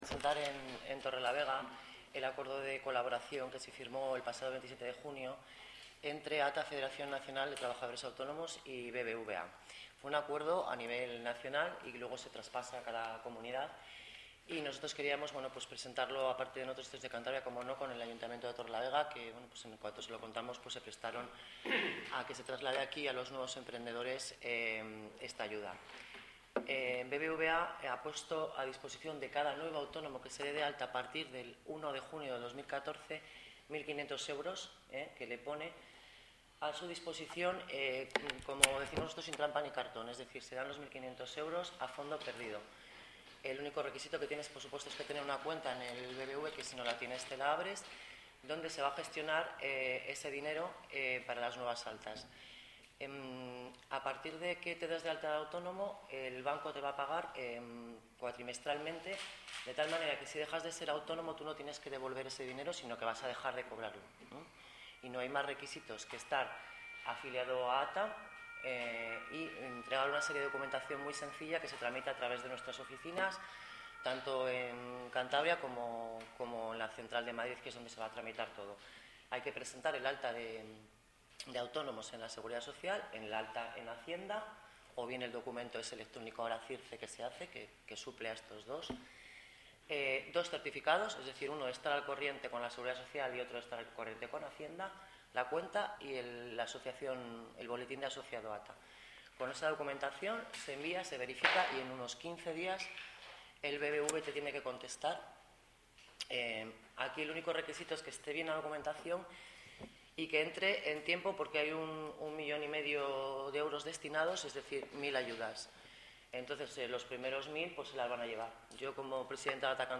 Javier L. Estrada, concejal de Empleo, Industria y Comercio